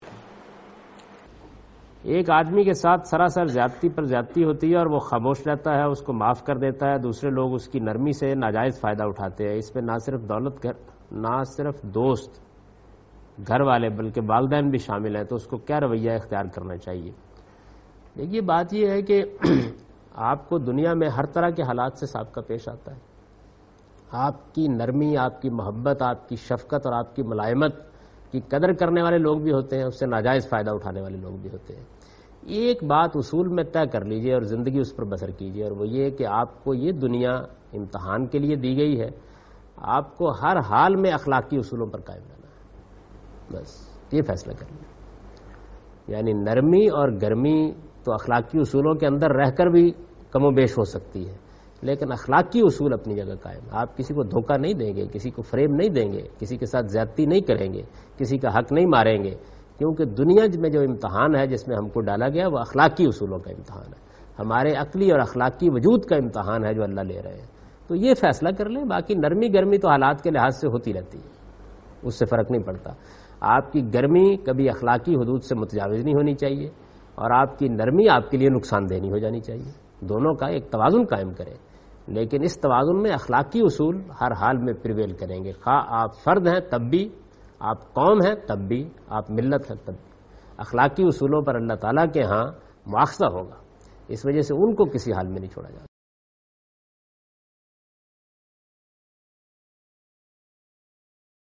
Javed Ahmad Ghamidi responds to the question 'Patience and Self Control what's the limit'?
اس سوال میں جاوید احمد غامدی :برداشت اور خود پرکنٹرول کی حد کیا ہے؟" کا جواب دے رہے ہیں